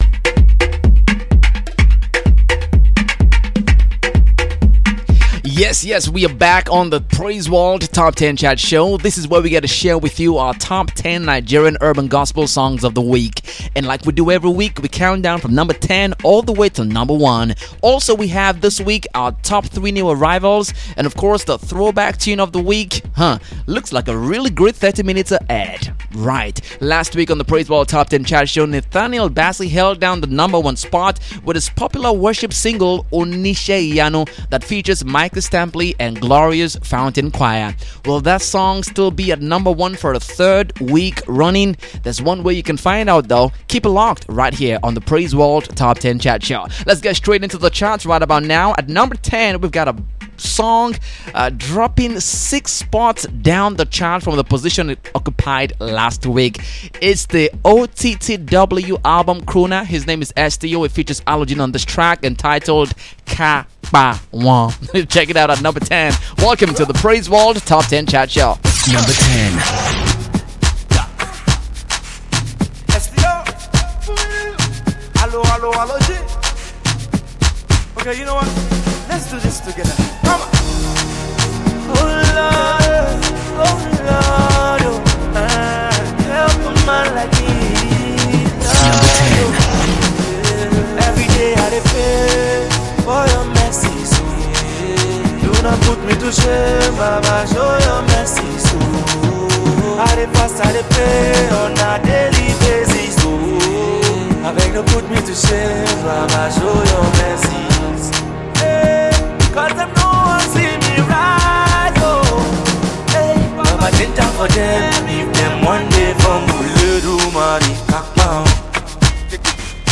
Here is a countdown show of our top 10 Nigerian urban gospel songs of the week. 30 minutes of fun